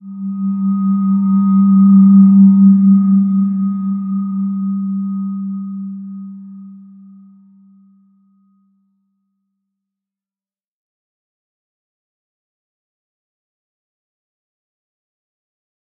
Slow-Distant-Chime-G3-mf.wav